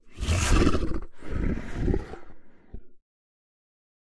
Creature_Sounds-Evil_Eye_Sound_2.ogg